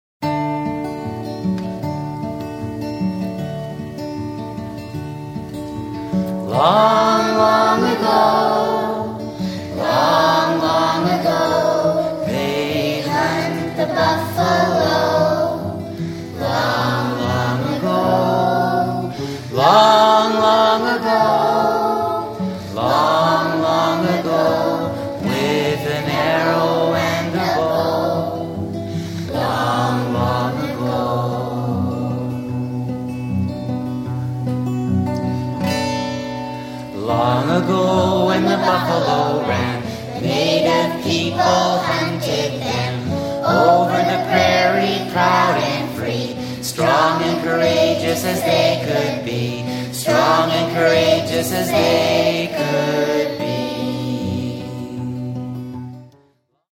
Lots of traditional Métis type fiddle music.